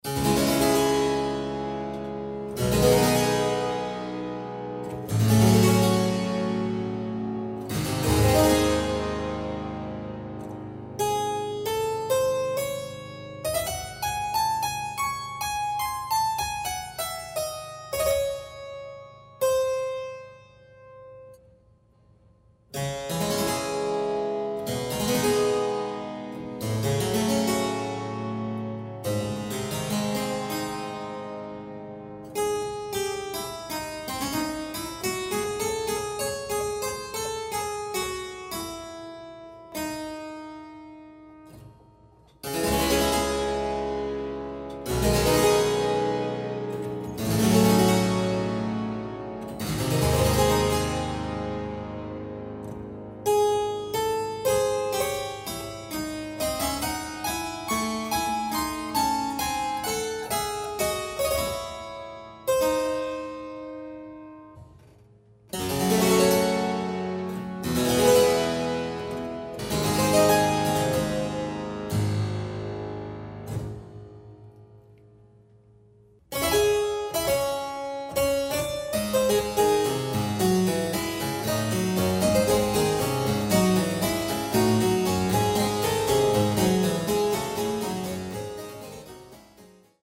A collection of old and new music for Harpsichord.